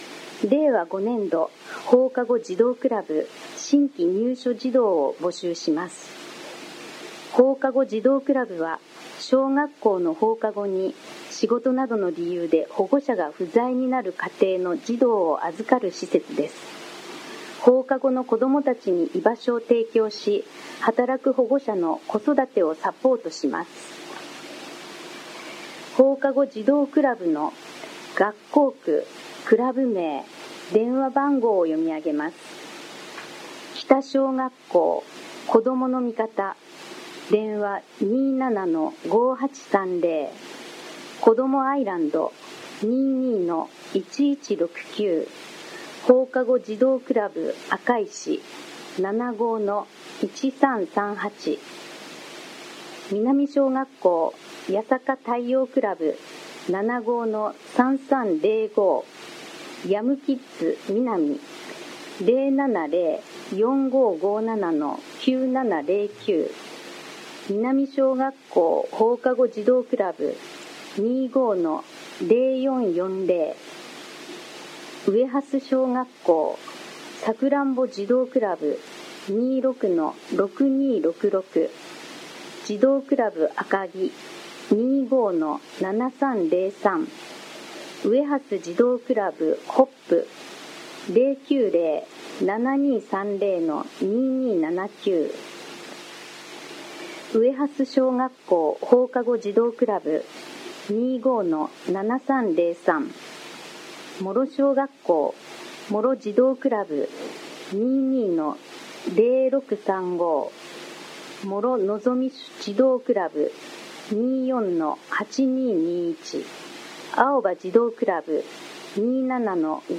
声の広報は目の不自由な人などのために、「広報いせさき」を読み上げたものです。
朗読
伊勢崎朗読奉仕会